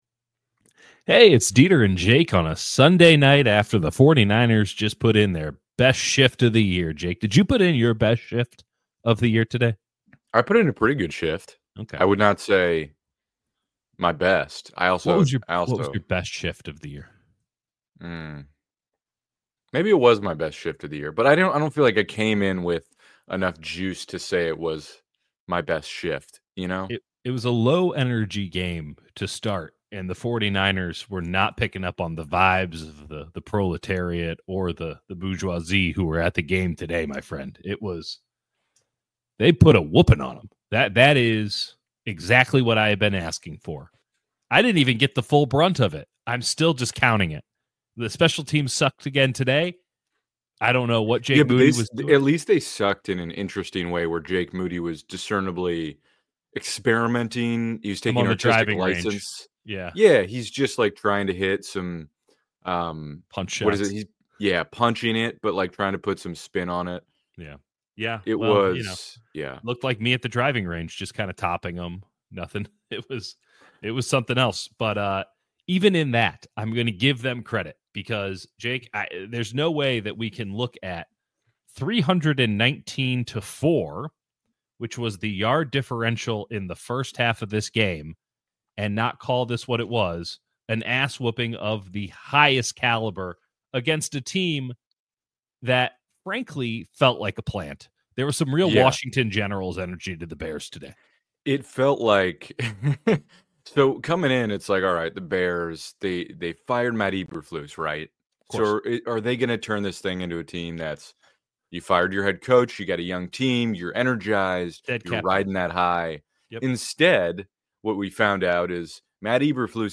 One-Off? A Trend? 49ers Steamroll the Bears | LIVE